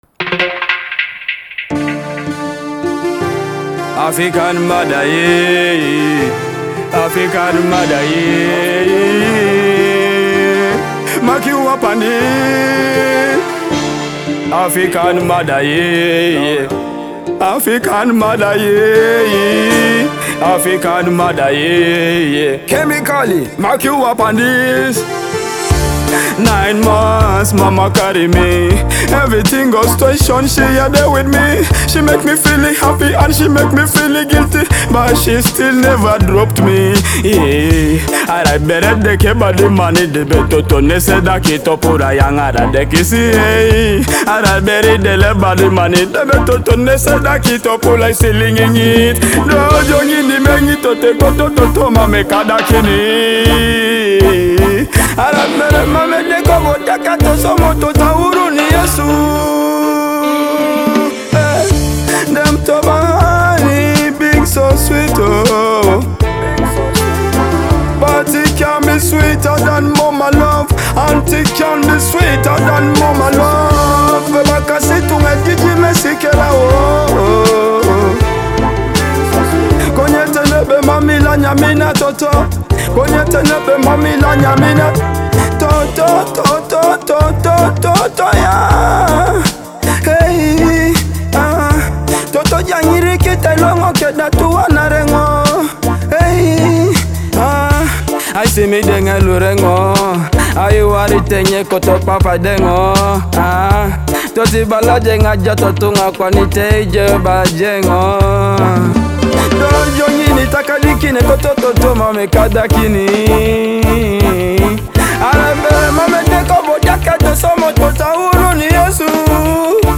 blending emotional lyrics with soulful Afrobeat vibes.